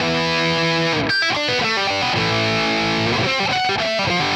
AM_RawkGuitar_110-E.wav